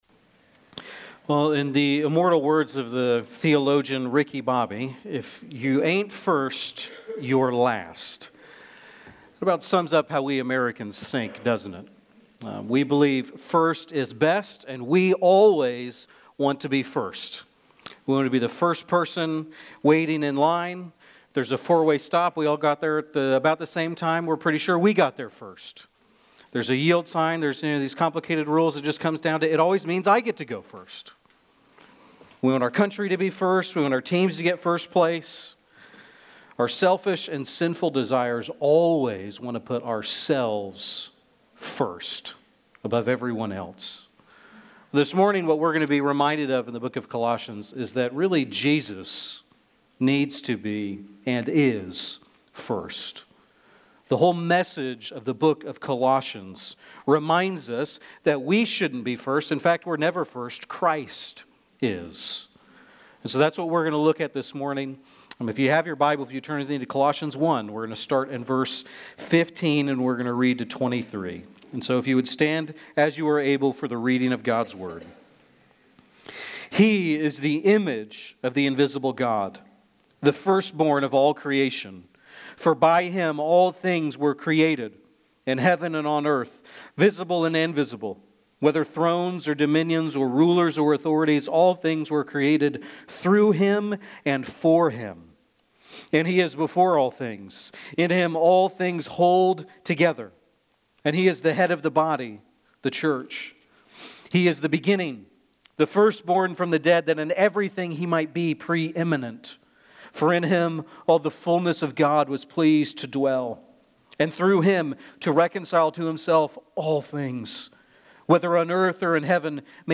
(V. 23) A transcript of the sermon is available here.